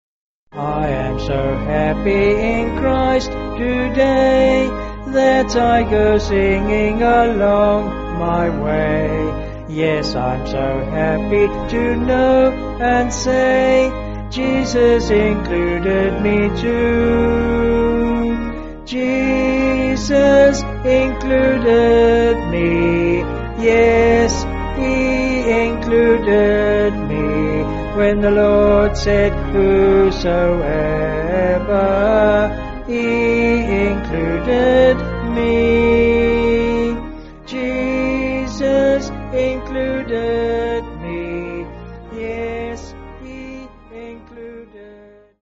(BH)   4/Ab
Vocals and Organ